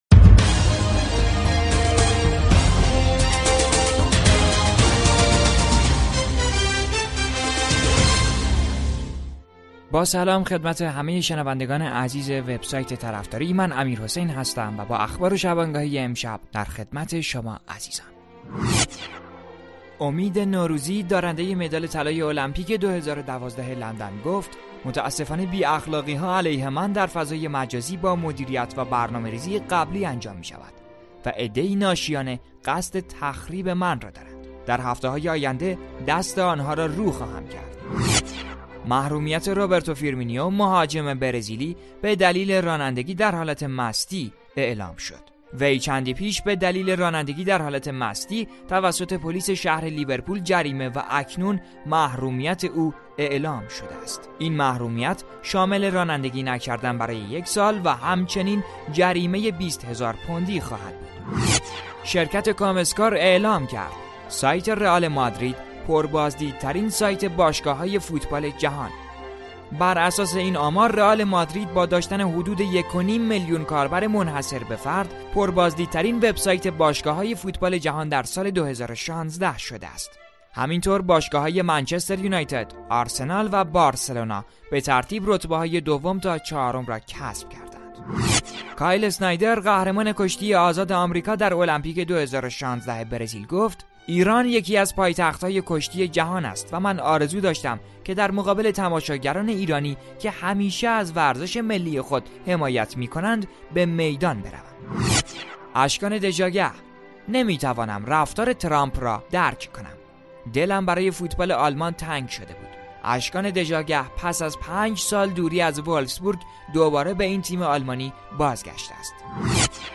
پادکست خبری